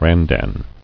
[ran·dan]